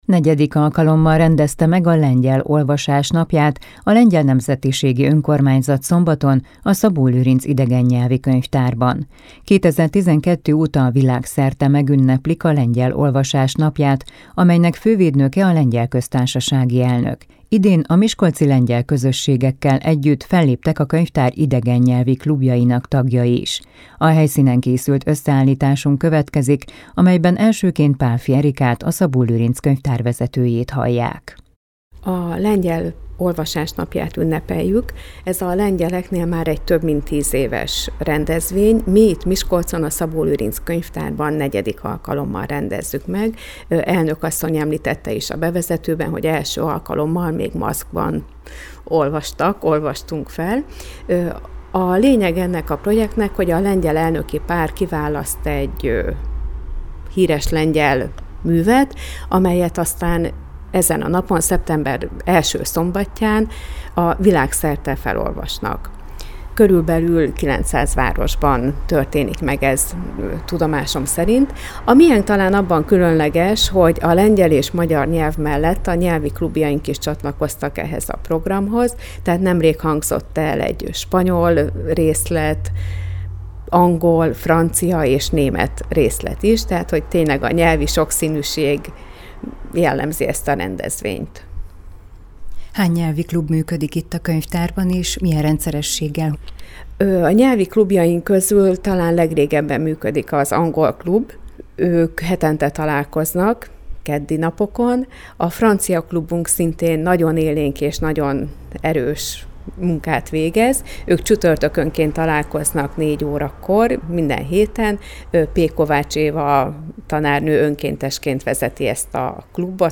0835_lengyel_felolvasas.mp3